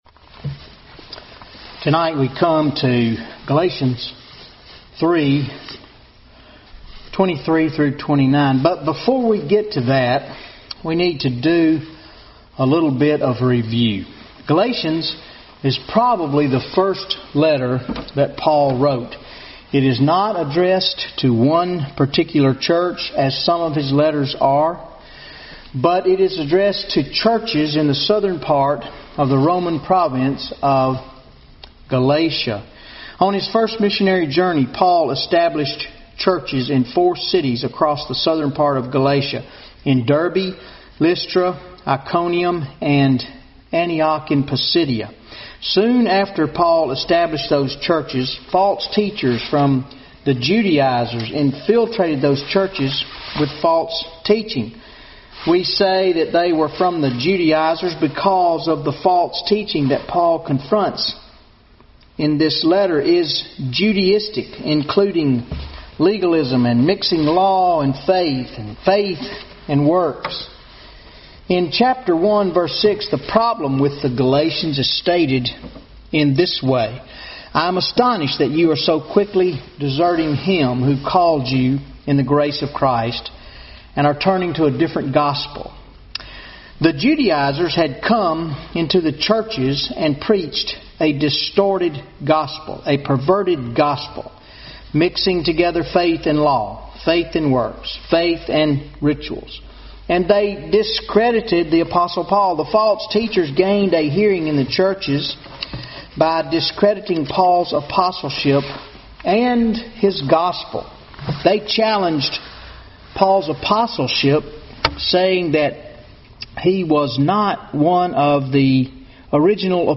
Wednesday Night Bible Study 01/21/2015 Galations 3:23-29 What is the Purpose of the Law?